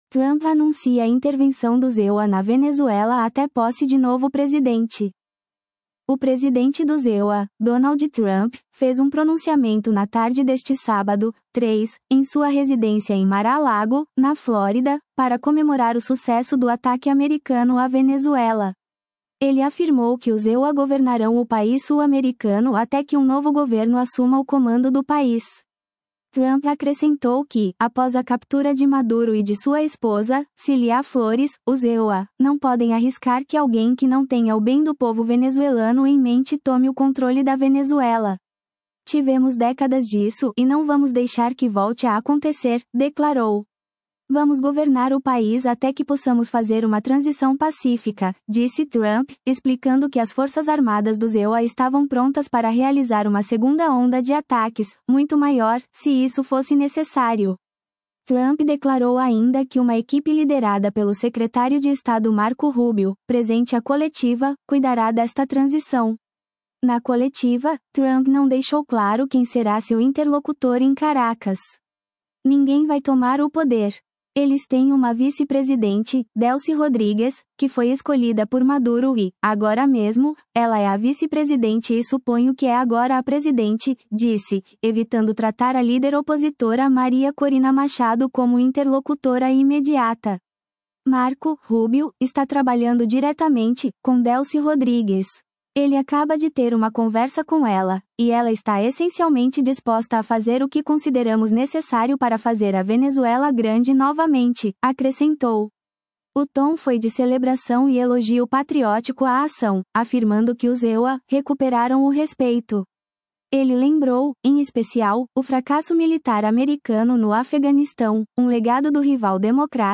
O presidente dos EUA, Donald Trump, fez um pronunciamento na tarde deste sábado (3), em sua residência em Mar-a-Lago, na Flórida, para comemorar o sucesso do ataque americano à Venezuela. Ele afirmou que os EUA governarão o país sul-americano até que um novo governo assuma o comando do país.
O tom foi de celebração e elogio patriótico à ação, afirmando que os EUA “recuperaram o respeito”.